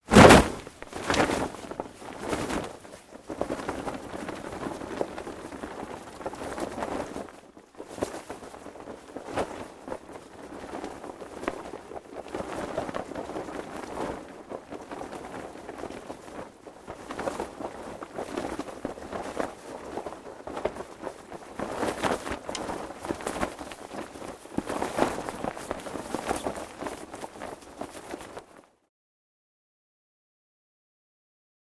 Звуки прыжка с парашютом
Вы услышите рев ветра, эмоции парашютиста, щелчки снаряжения и другие детали этого экстремального опыта.
Вариант 2 (а затем он качается на ветру в полете)